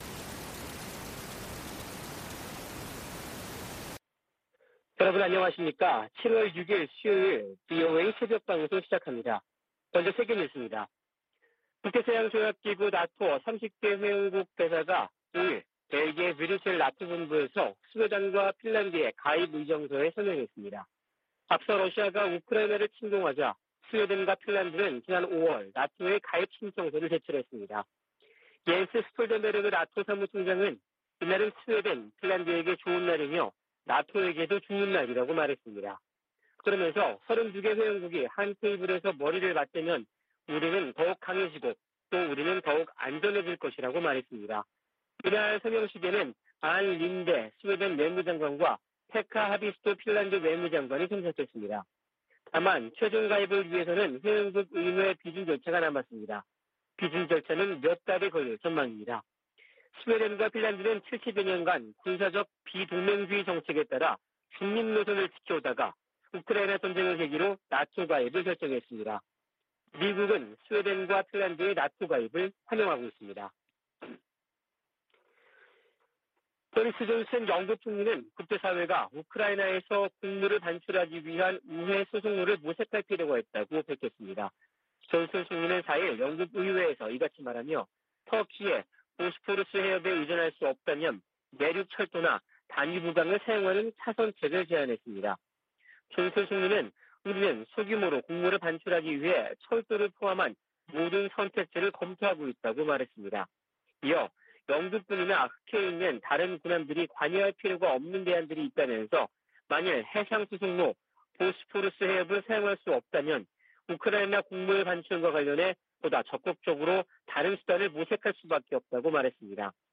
VOA 한국어 '출발 뉴스 쇼', 2022년 7월 6일 방송입니다. 한국 국방부는 5일 미 공군 스텔스 전투기 F-35A 6대가 한반도에 전개됐다고 밝혔습니다. 북한의 7차 핵실험 가능성이 제기되는 가운데 미 공군 특수 정찰기들이 한반도와 일본, 동중국해 등에서 포착되고 있습니다. 미 하원에 타이완과 한국 등 인도태평양 동맹국들에 대한 방산물자 인도가 효율적으로 이뤄지도록 하는 법안이 발의됐습니다.